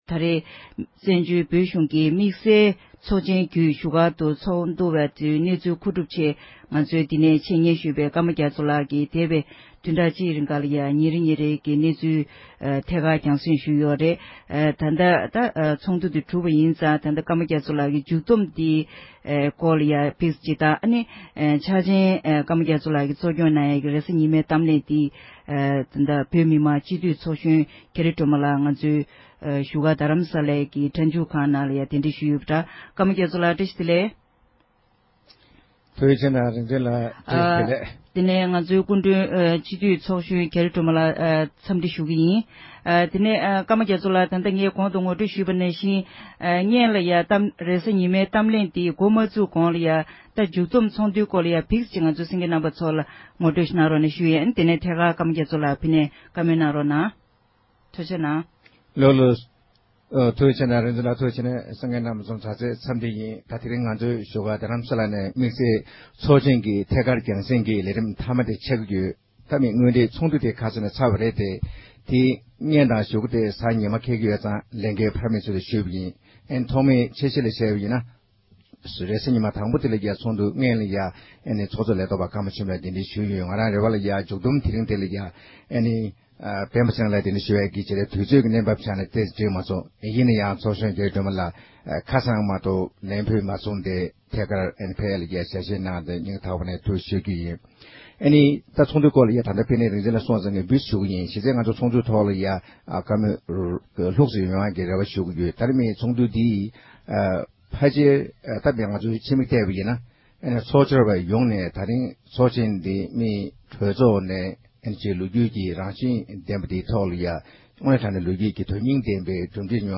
བོད་མི་མང་སྤྱི་འཐུས་ཚོགས་གཞོན་རྒྱ་རི་སྒྲོལ་མ་ལགས་ནས་དམིགས་བསལ་ཚོགས་འདུའི་སྐོར་བགྲོ་གླེང་གནང་བ།